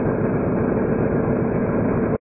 med_ship_a_s.ogg